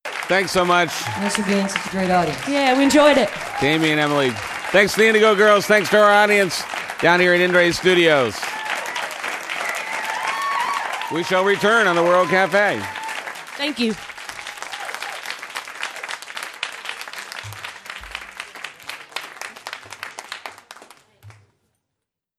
lifeblood: bootlegs: 1999-10-05: world cafe recording session at inderay studios - philadelphia, pennsylvania
11. interview (0:22)